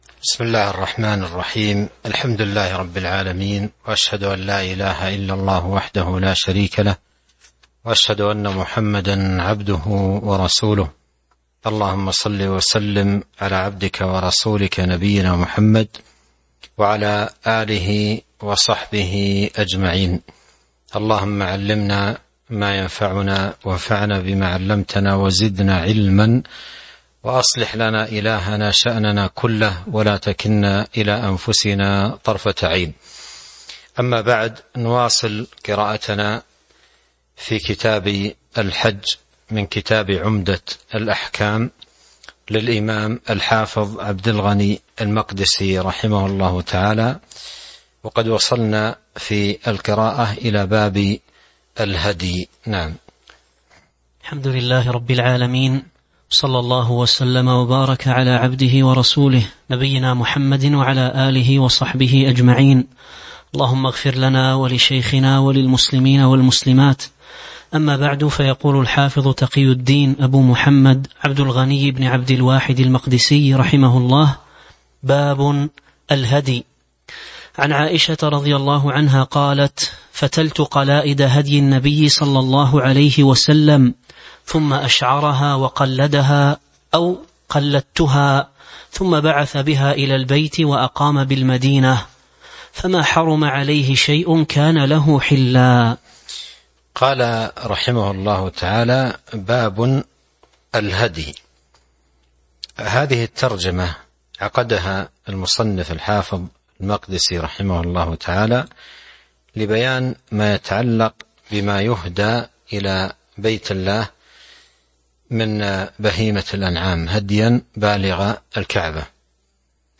تاريخ النشر ٢٧ ذو القعدة ١٤٤٢ هـ المكان: المسجد النبوي الشيخ